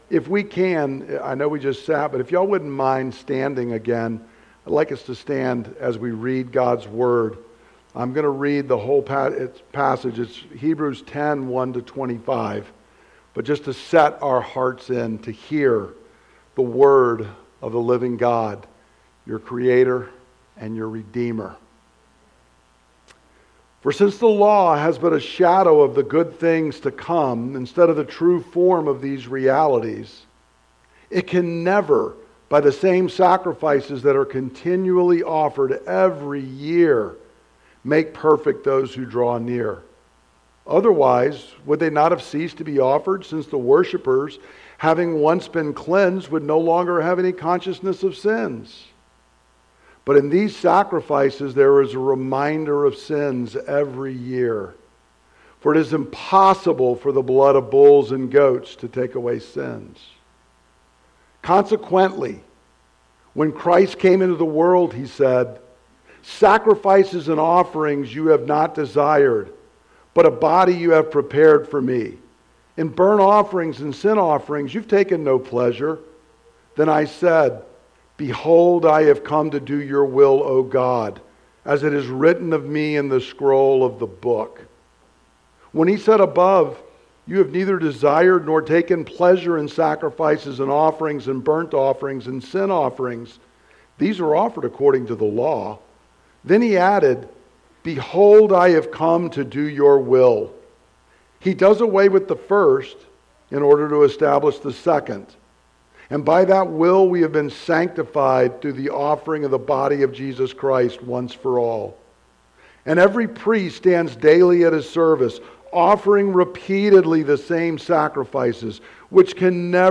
» Sermons